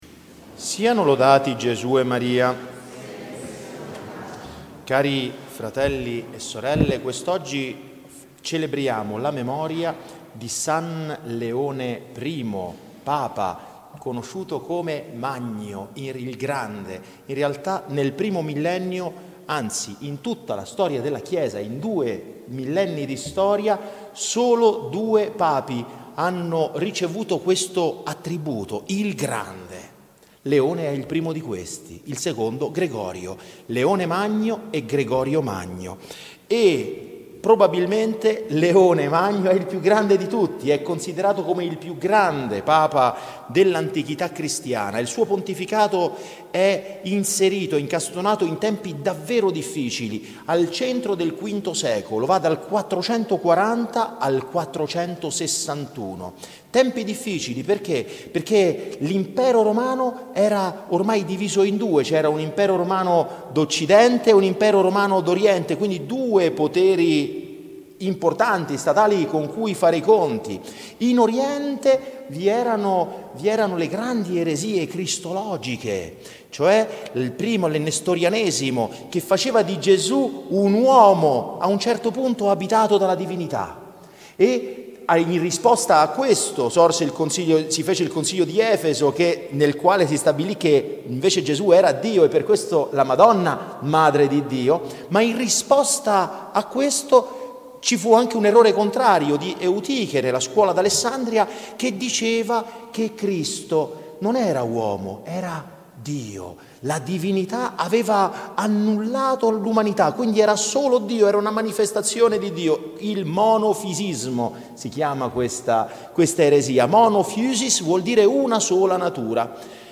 Omelia della s. Messa del 27 Agosto 2024, Memoria di Santa Monica